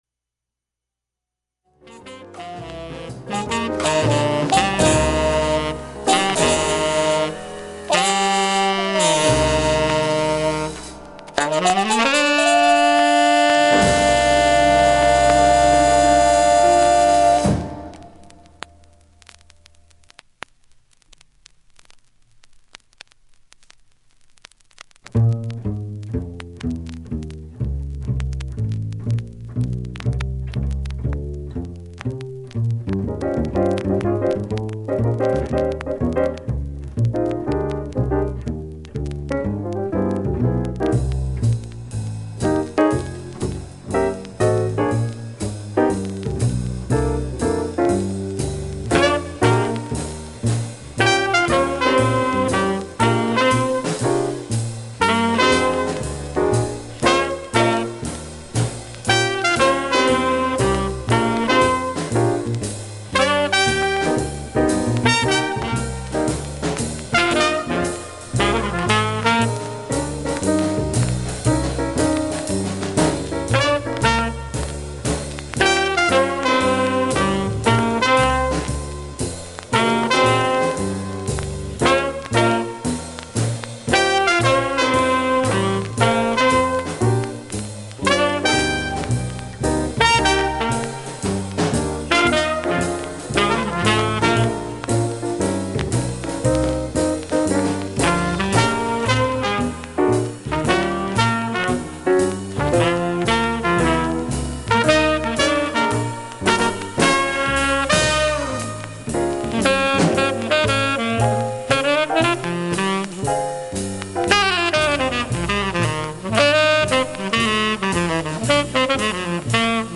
全体にバックチリプツが入ります。
あまりストレスなく音質、音圧良いです。
◆ステレオ針での試聴です。
◆ＵＳＡ盤オリジナルMONO